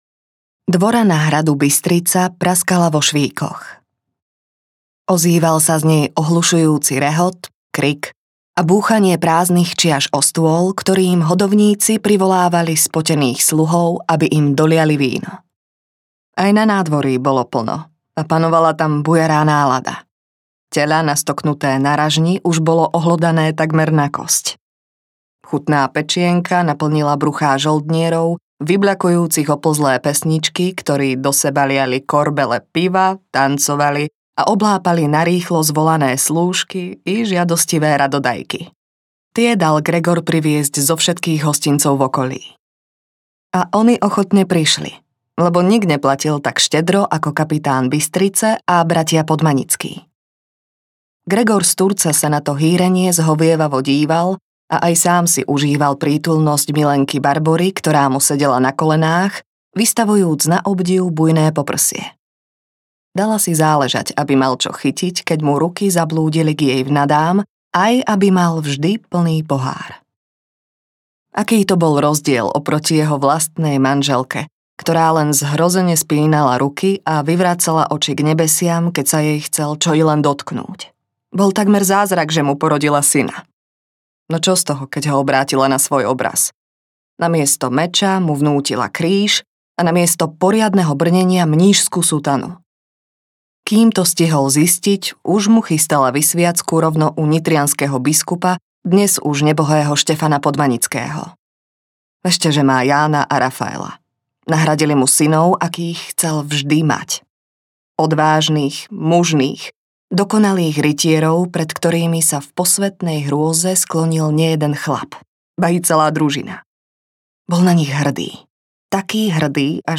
Súboj s láskou audiokniha
Ukázka z knihy